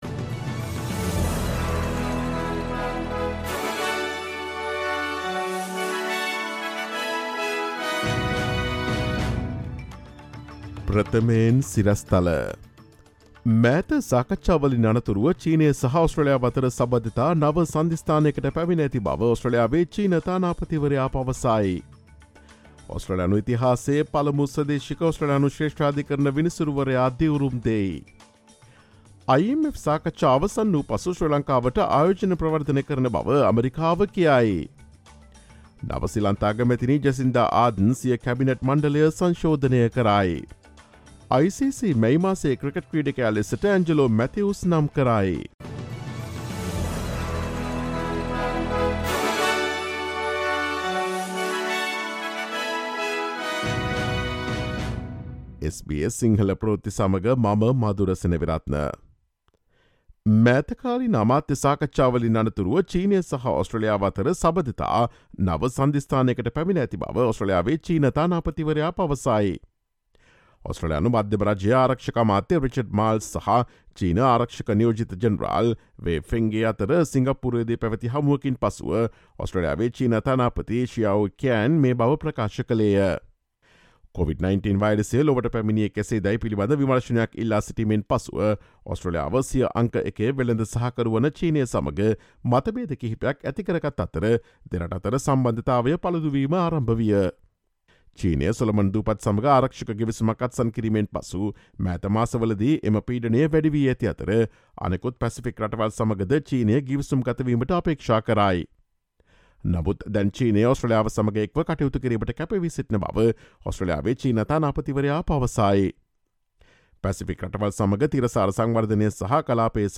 ඔස්ට්‍රේලියාවේ සහ ශ්‍රී ලංකාවේ නවතම පුවත් මෙන්ම විදෙස් පුවත් සහ ක්‍රීඩා පුවත් රැගත් SBS සිංහල සේවයේ 2022 ජුනි 14 වන දා අඟහරුවාදා වැඩසටහනේ ප්‍රවෘත්ති ප්‍රකාශයට සවන් දීමට ඉහත ඡායාරූපය මත ඇති speaker සලකුණ මත click කරන්න.